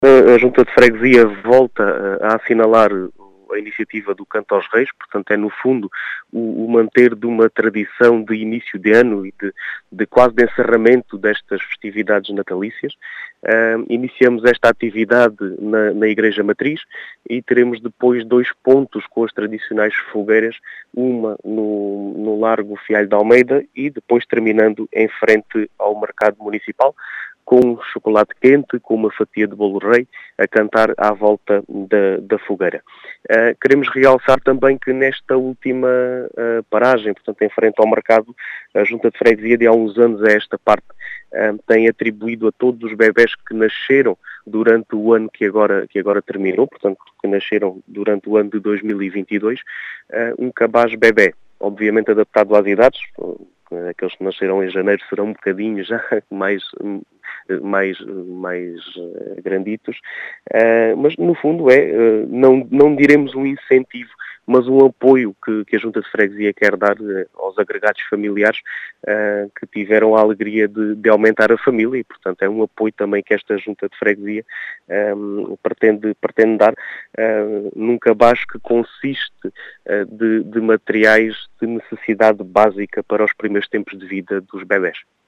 As explicações são de Diogo Conqueiro, presidente da junta de freguesia de Vila de Frades, que fala de uma iniciativa que pretende “manter uma tradição” que culmina com a entrega de um “apoio” aos agregados familiares que ampliaram a família em 2022.